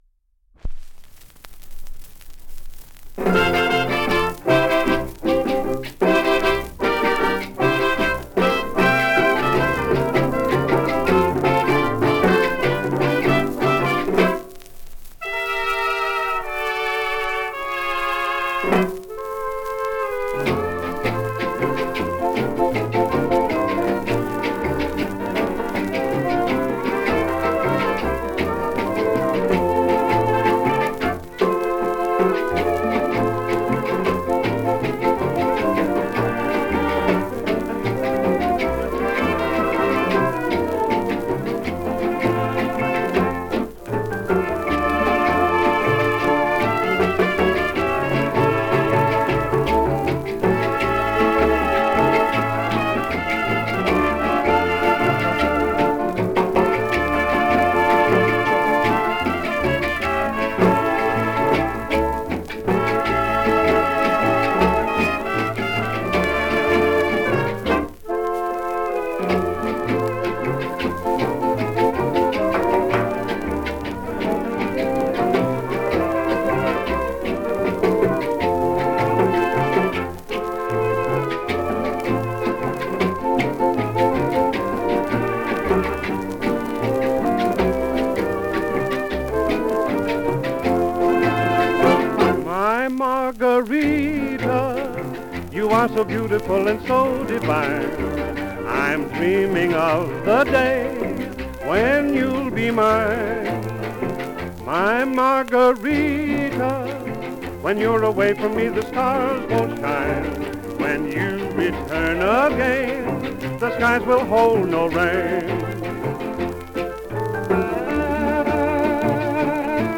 78rpm
Оцифровка на 78 об/мин.